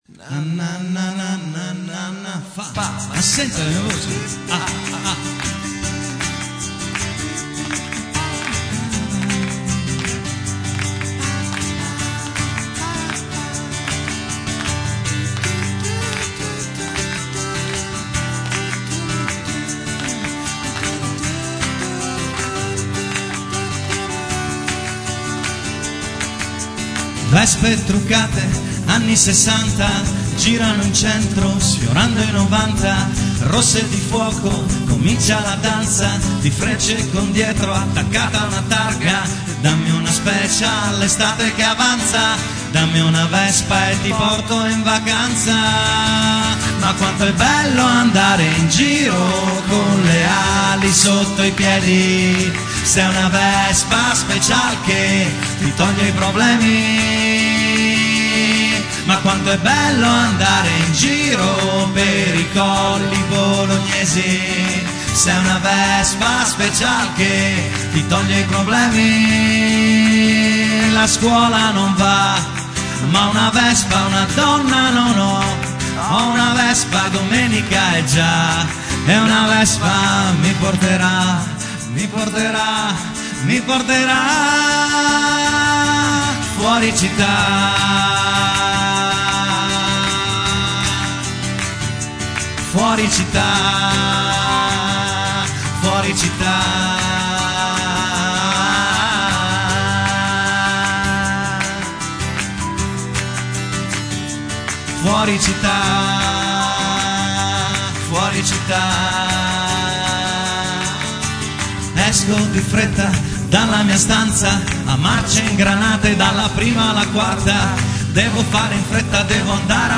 CANZONE - VERSIONE ACUSTICA